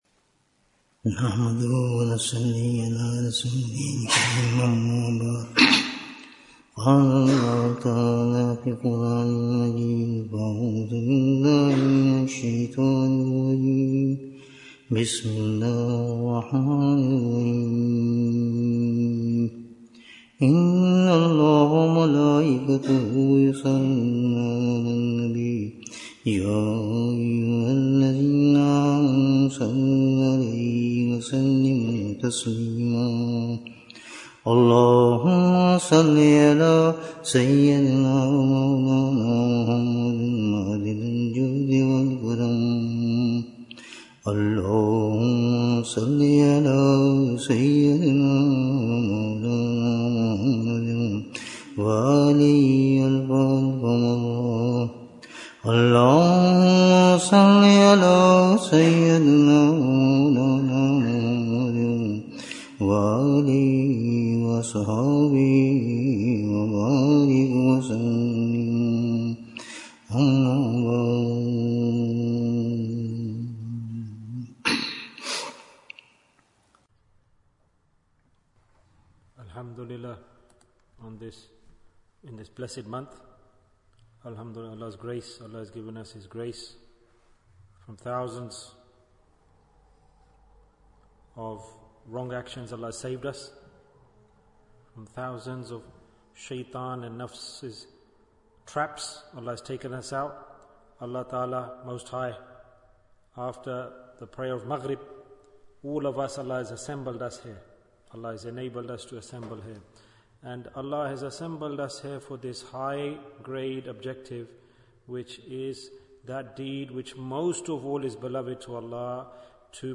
Wake Up? Bayan, 56 minutes22nd August, 2024